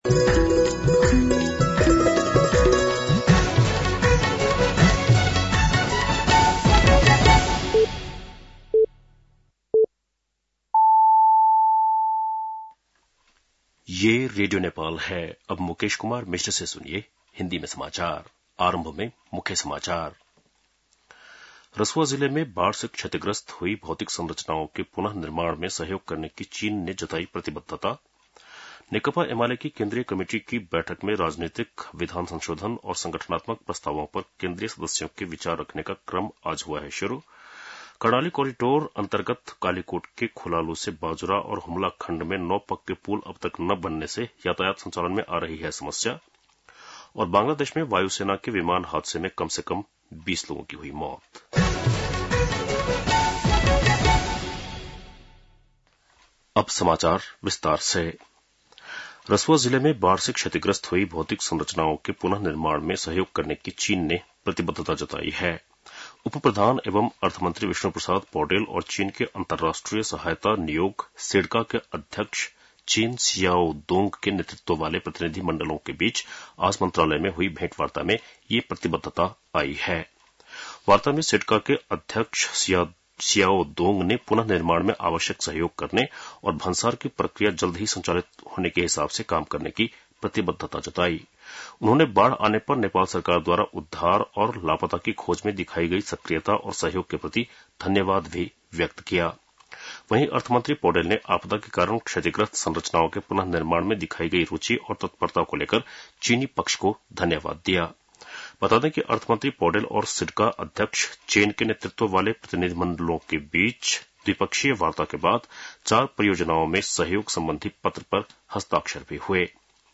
बेलुकी १० बजेको हिन्दी समाचार : ५ साउन , २०८२